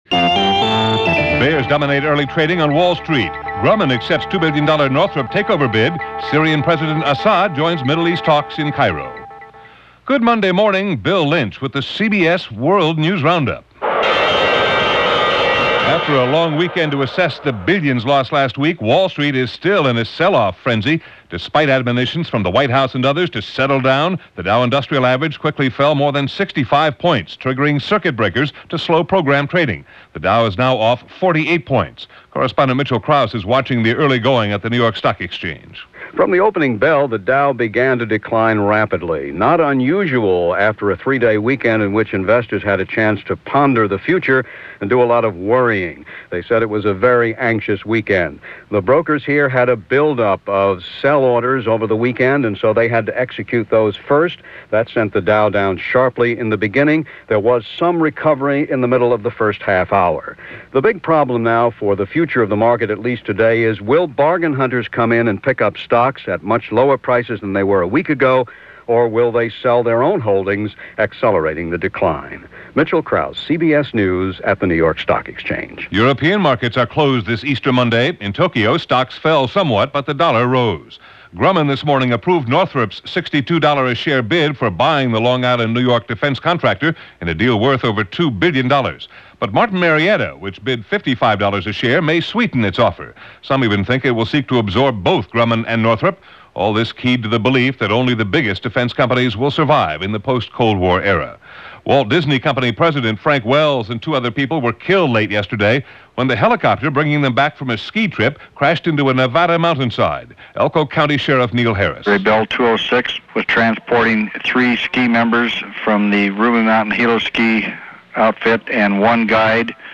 – CBS World News Roundup – April 4, 1994 – Gordon Skene Sound Collection –